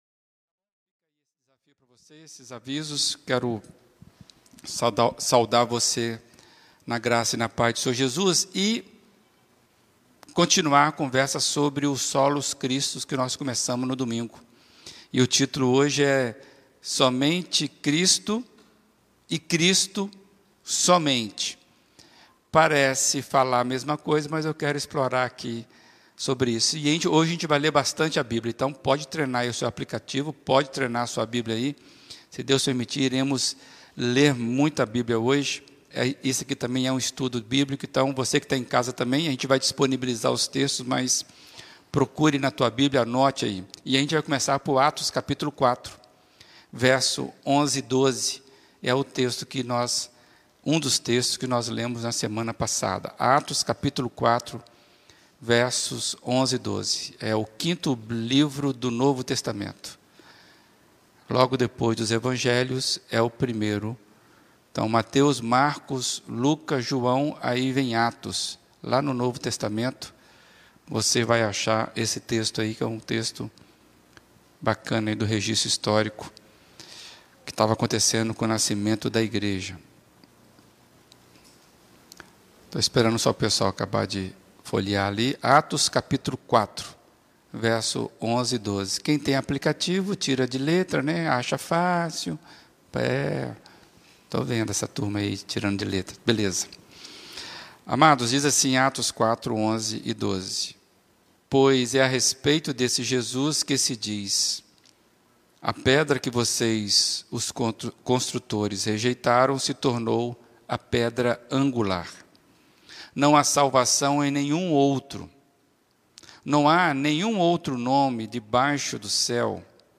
Mensagem apresentada